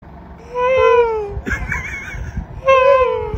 Camel Cry Sound Button - Free Download & Play